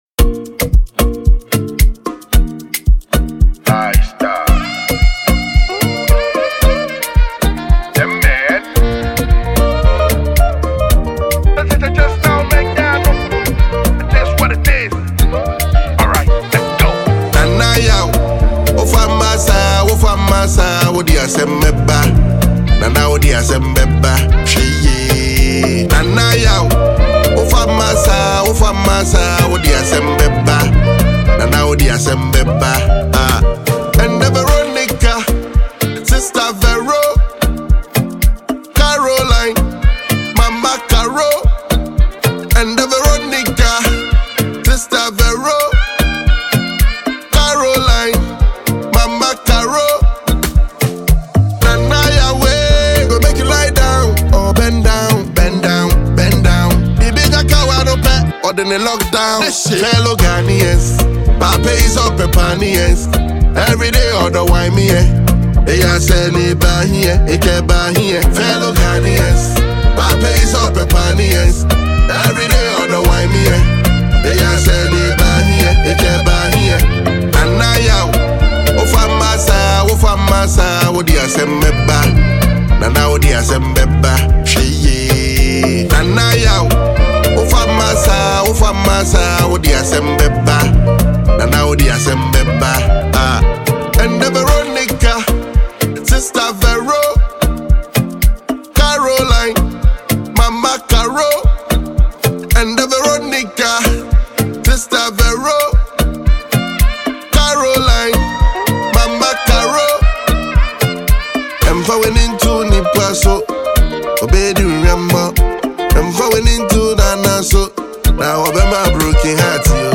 hiplife
flowing smoothly over a bright, dance-ready instrumental.